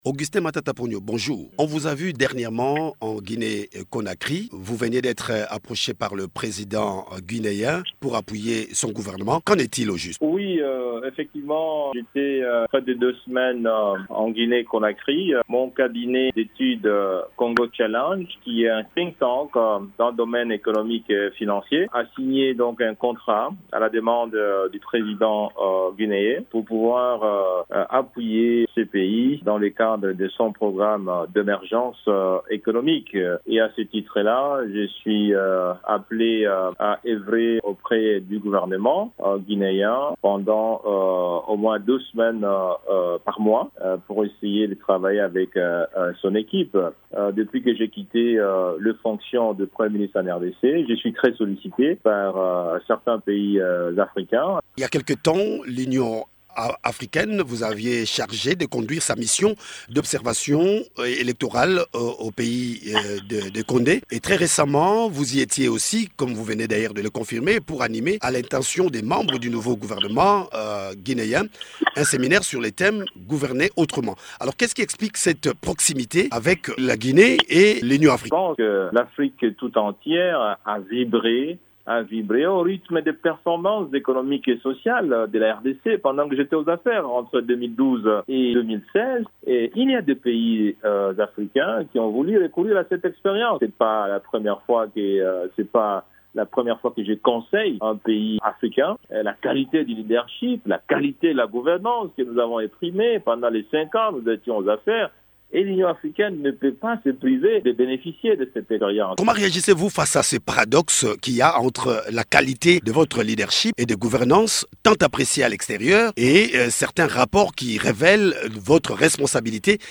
Entretien.